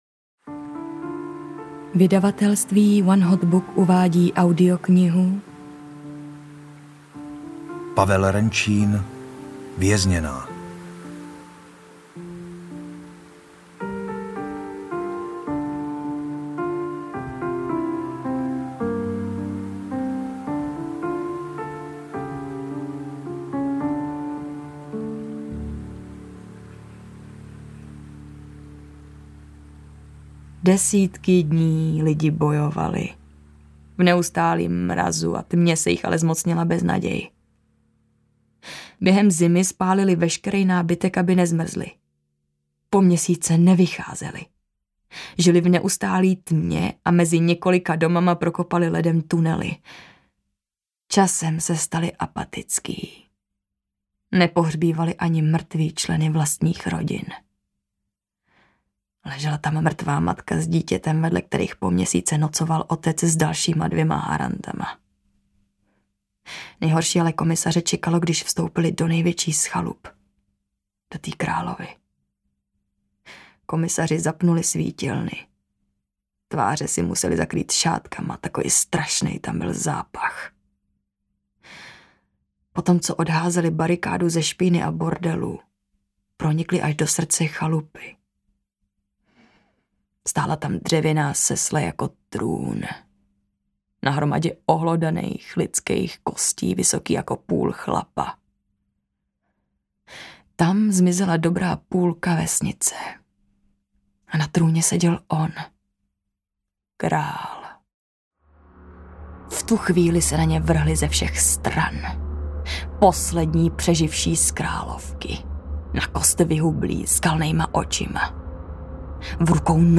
Vězněná audiokniha
Ukázka z knihy